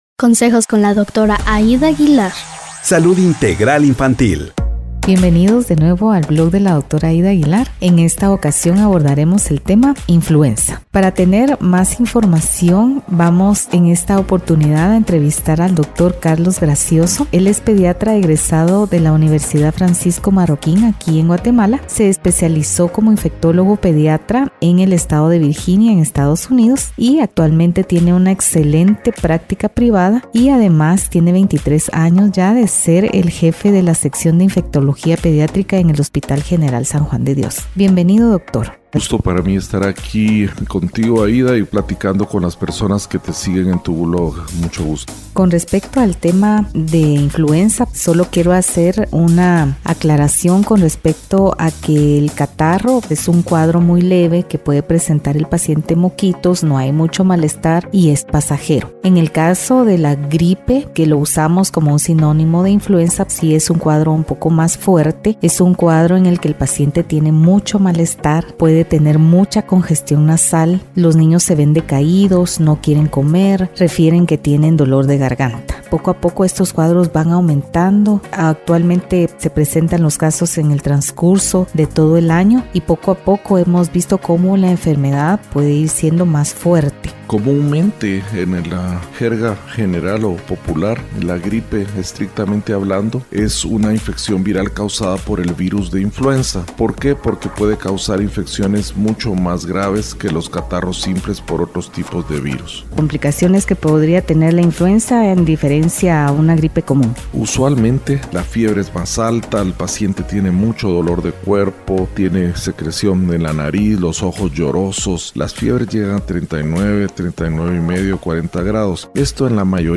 Todo lo que tienes que saber sobre la Influenza. Entrevista